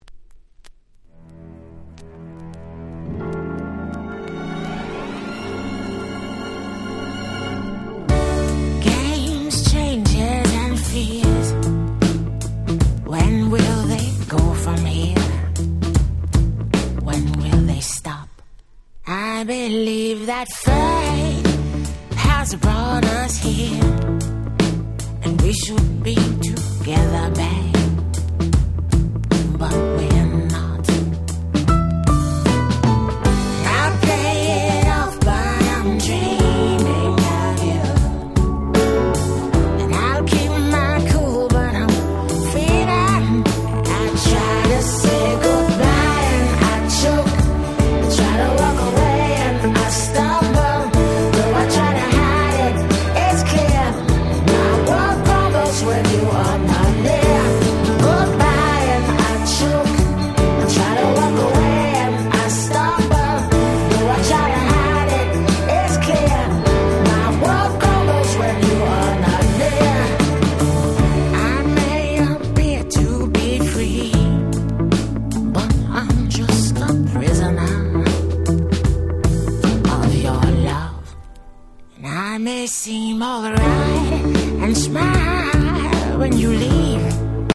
99’ Super Hit R&B/Neo Soul !!